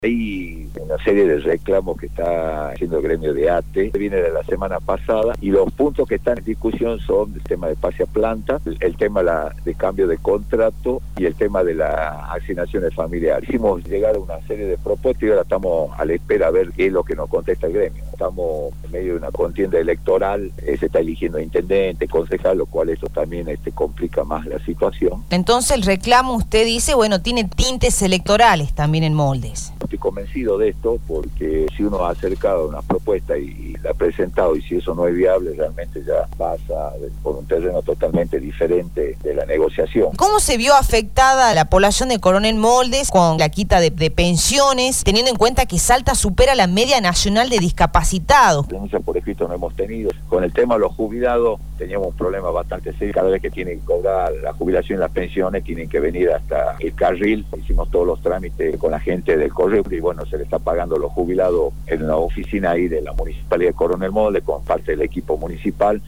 El interventor de Coronel Moldes, Rodolfo Antonelli, se refirió al conflicto con empleados municipales y sostuvo que se dificulta la negociación por estar en medio de una contienda electoral.
RODOLFO-ANTONELLI-INTERVENTOR-DE-CORONEL-MOLDES-SE-REFIRIO-AL-CONFLICTO-CON-EMPLEADOS-MUNICIPALES.mp3